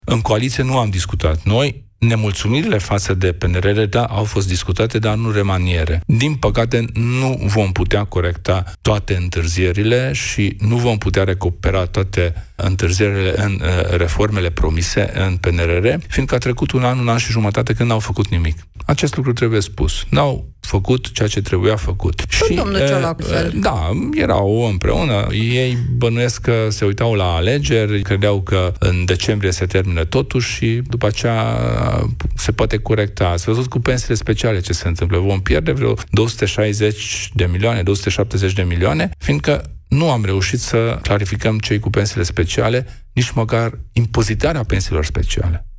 Programe > Piața Victoriei > Kelemen Hunor: „Coaliția nu a discutat despre remanierea Guvernului.